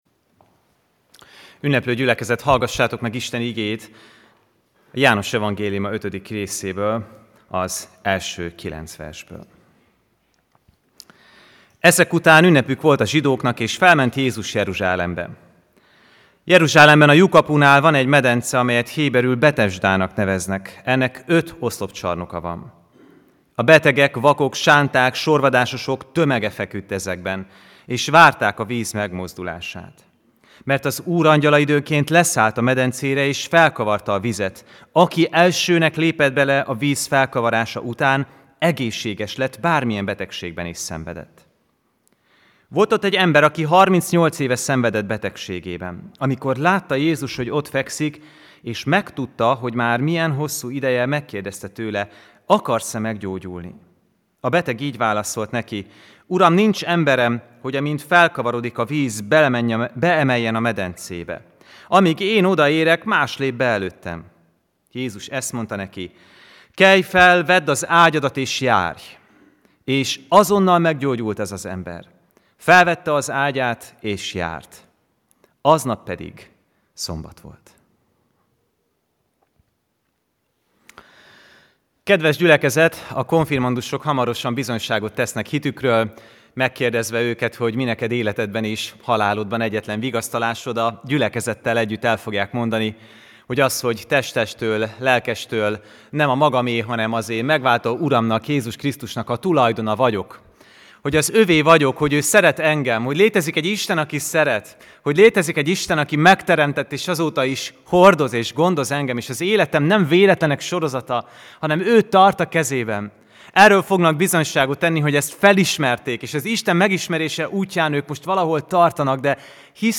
AZ IGEHIRDETÉS LETÖLTÉSE PDF FÁJLKÉNT AZ IGEHIRDETÉS MEGHALLGATÁSA
Pünkösd vasárnap